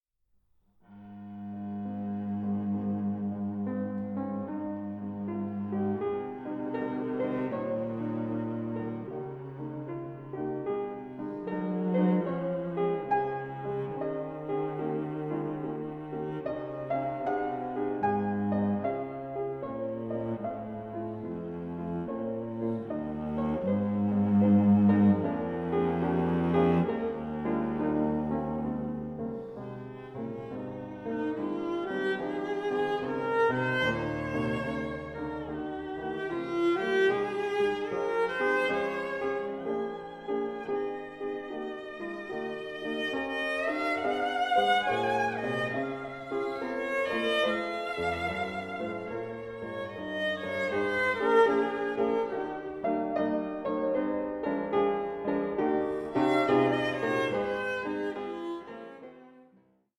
1837 Érard fortepiano